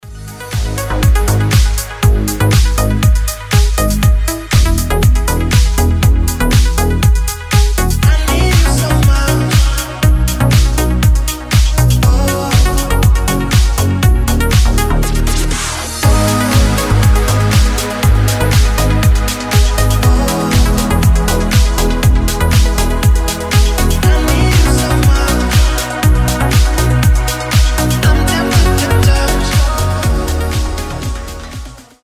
• Качество: 320, Stereo
мужской голос
громкие
deep house
nu disco
Сочное звучание deep disco